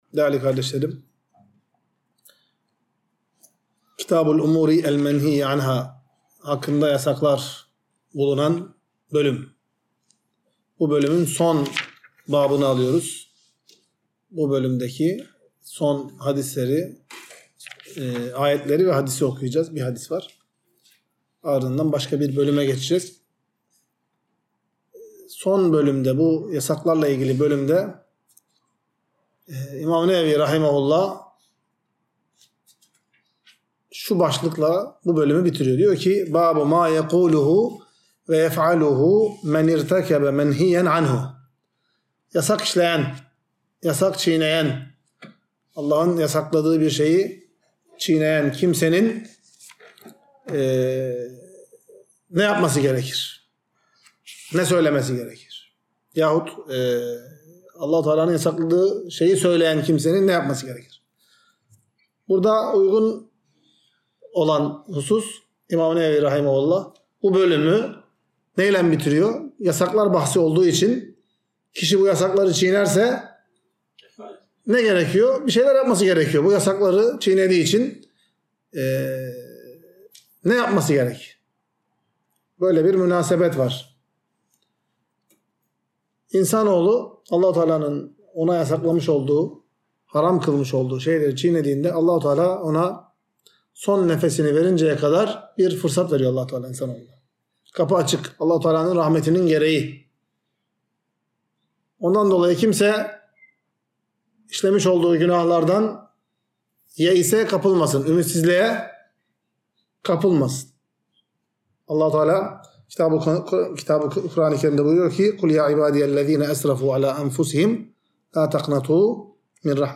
Ders.mp3